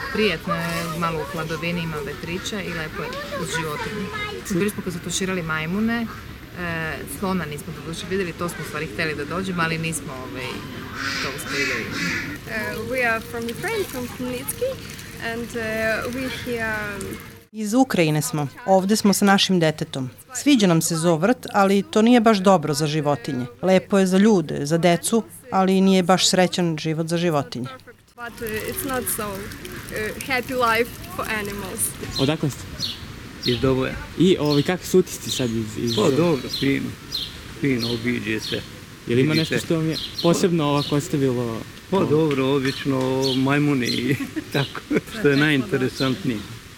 Posetioci o zoo vrtu